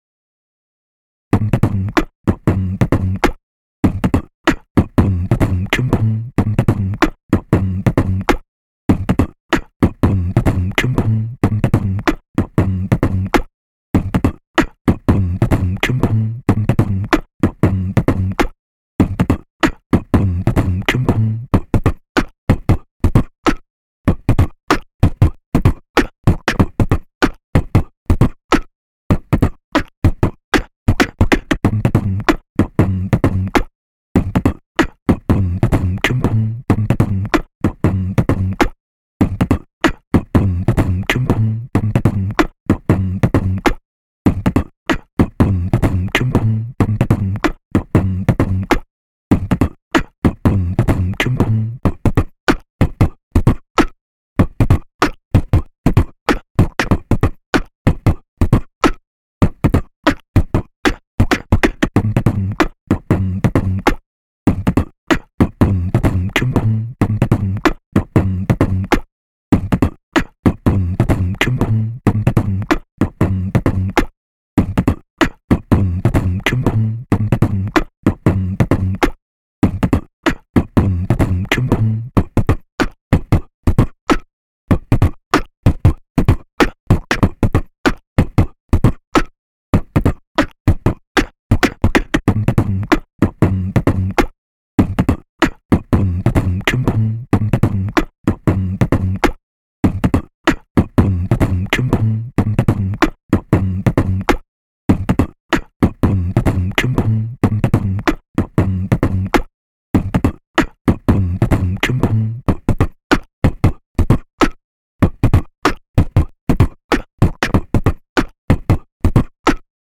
Po prostu włącz, aby poćwiczyć beatbox z bitem do pomocy. Bit jest na tyle prosty, że możesz dodać tam wiele swoich dźwięków, efektów czy skreczy. 4 takty bitu z basem przeplatają się z 4 taktami przejścia perkusyjnego.
loop 4 (4/4, 95bpm, 5:05min, 5.6mb)